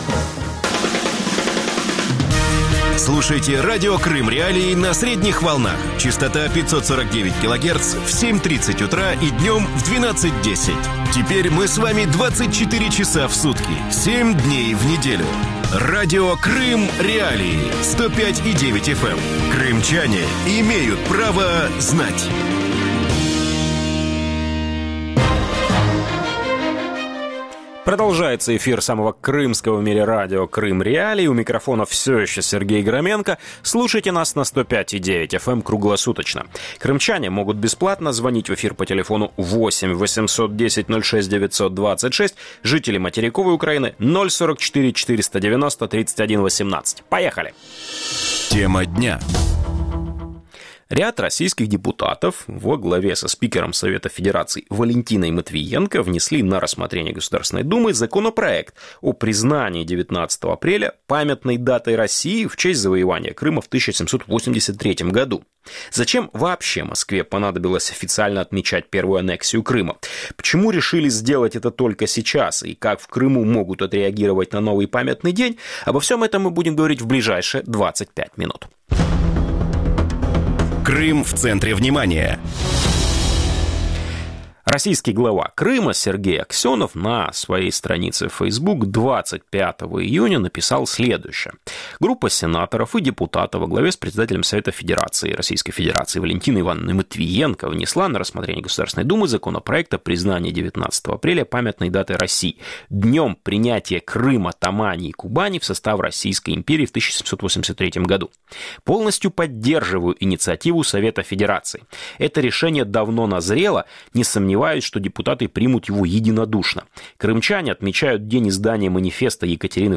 Радио Крым.Реалии в эфире 24 часа в сутки, 7 дней в неделю.